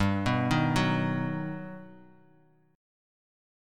Listen to G+M7 strummed